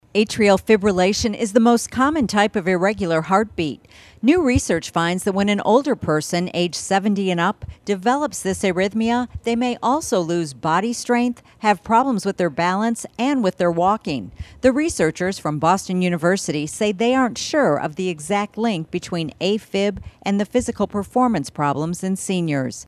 Health reporter